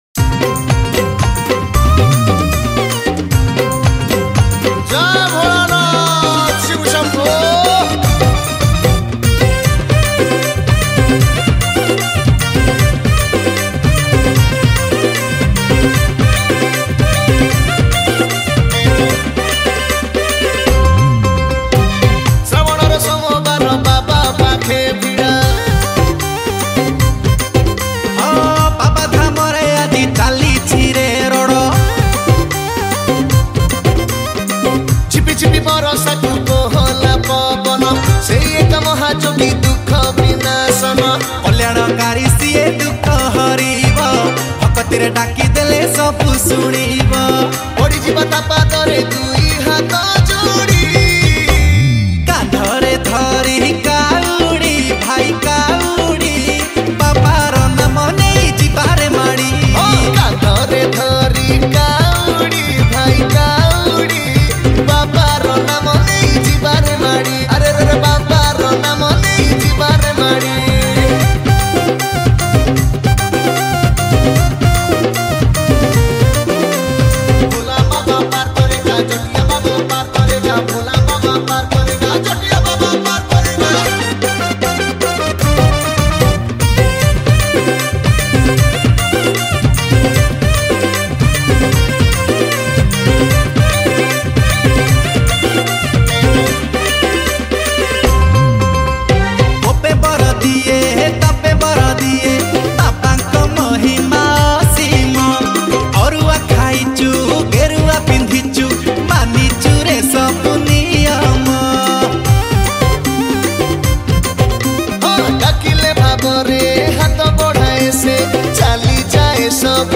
• Category :Bolbum Special Song